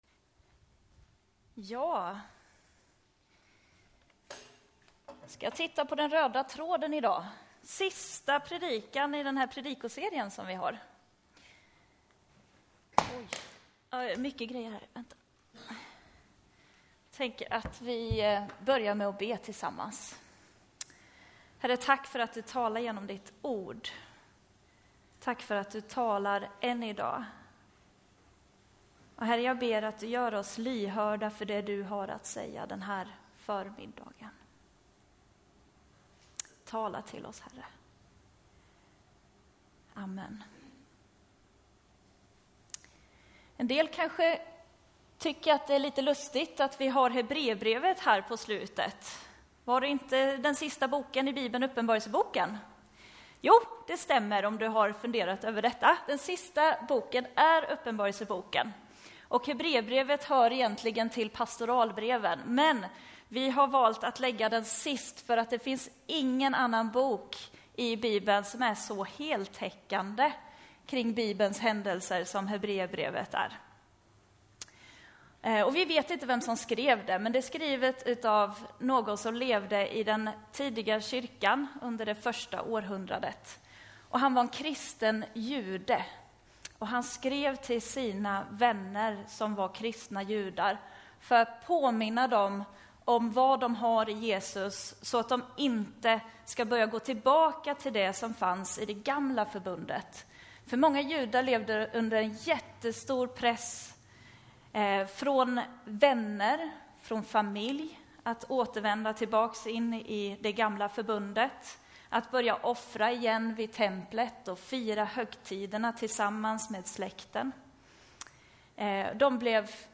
Predikoserie: Genom NT (våren 2018)